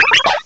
cry_not_pachirisu.aif